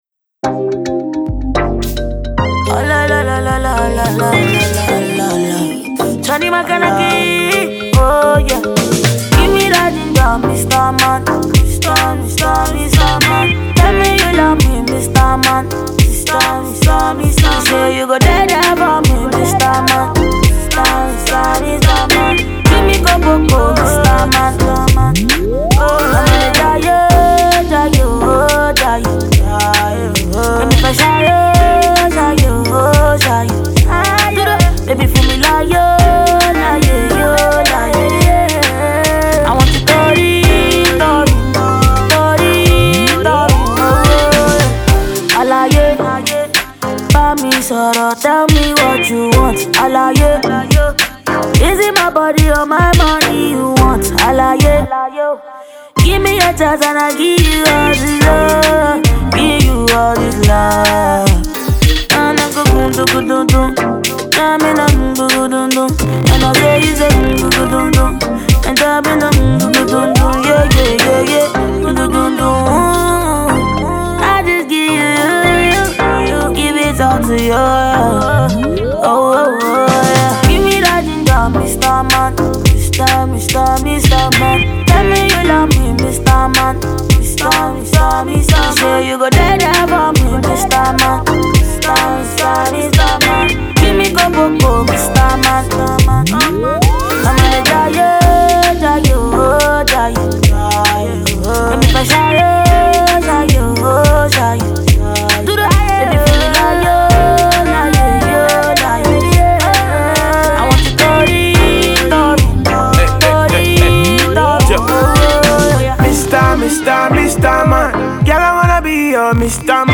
This is an afrobeat fused song